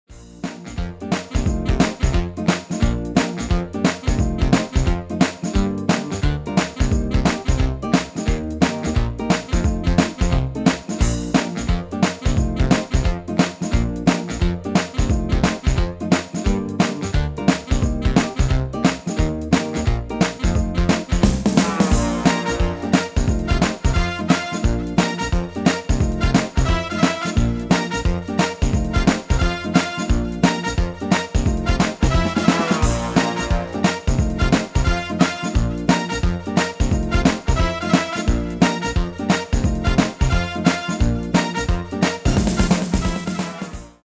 MP3 backing track arranged in the style of:
Genre: Pop & Rock Internationaal
Key: Eb
File type: 44.1KHz, 16bit, Stereo
Demo's played are recordings from our digital arrangements.